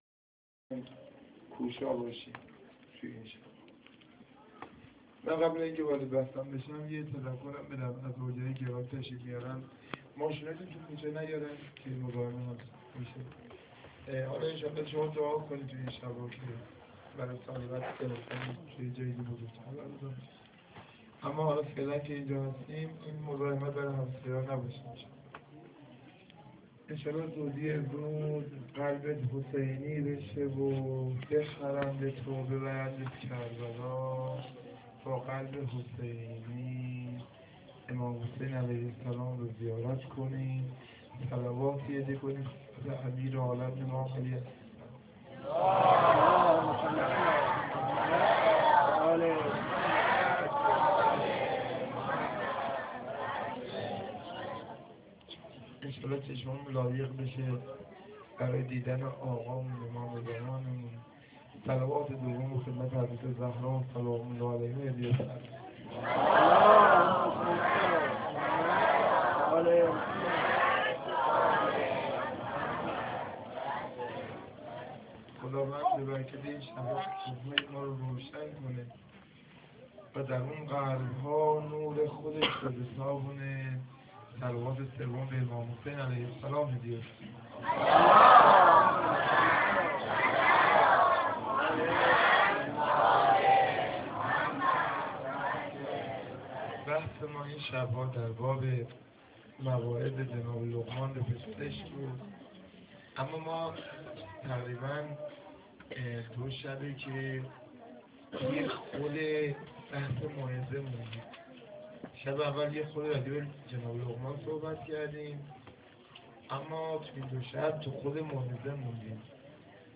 سخنرانی3.amr